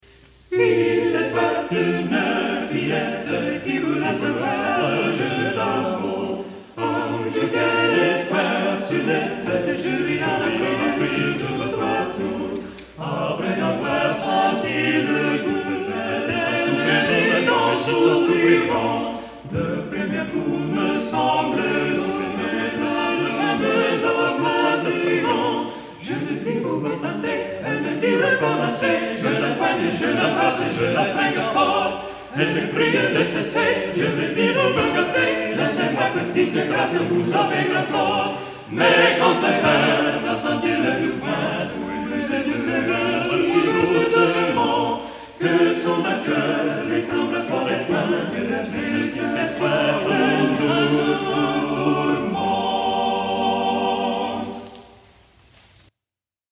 | Vocal ensemble 'Seven Ages' 1979